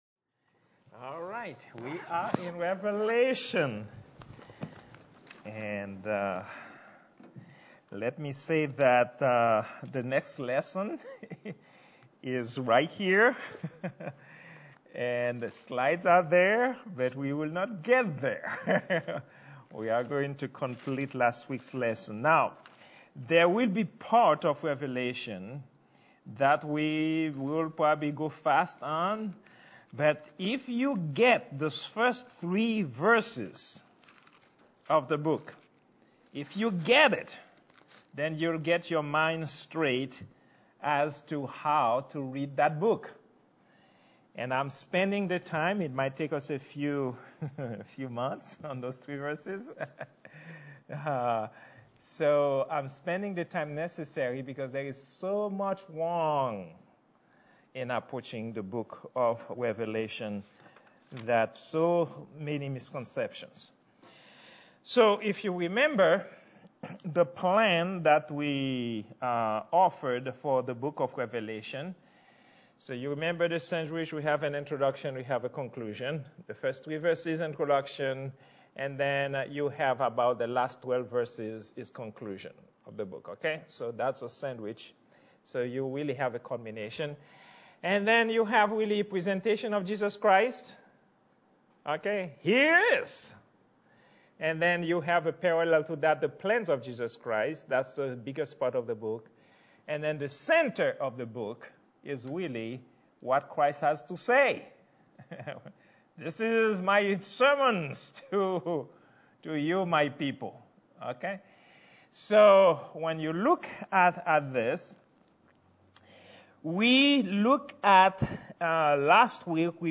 Prayer_meeting_07_13_2022.mp3